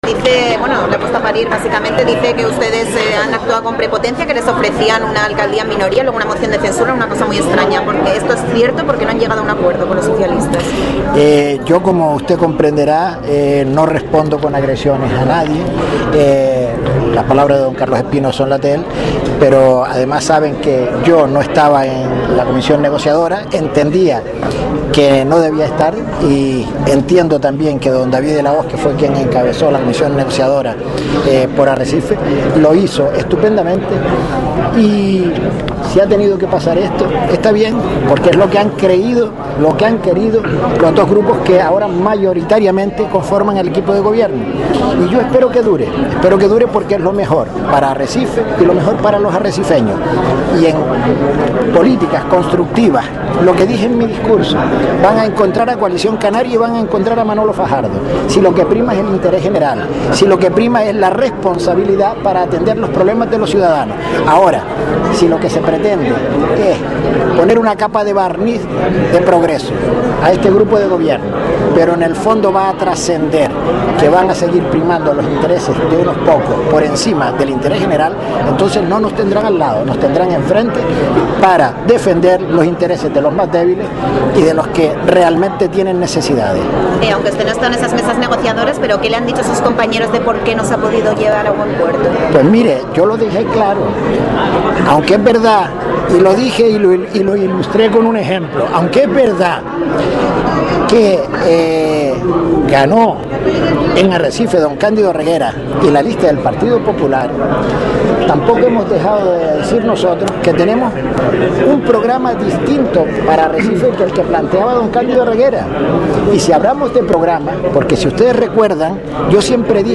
Escuche aquí las declaraciones de Reguera, Montelongo, Fajardo Feo y Espino tras el pleno de Arrecife